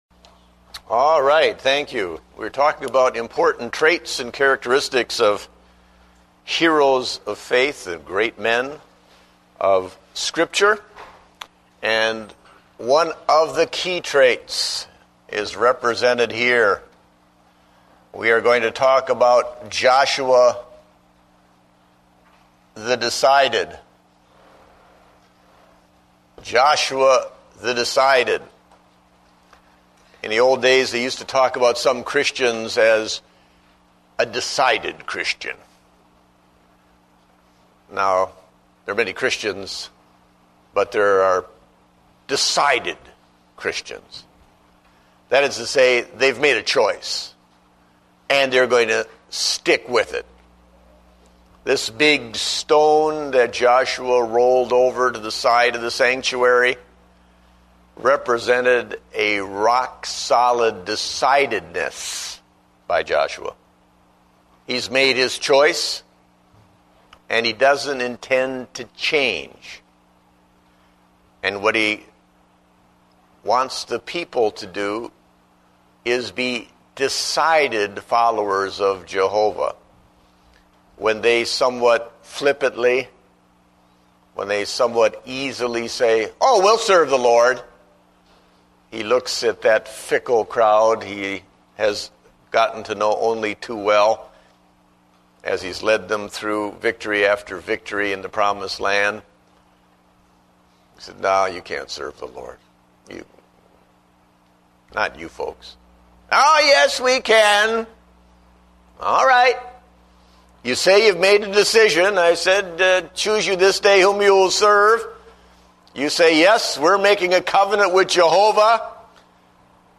Date: July 26, 2009 (Adult Sunday School)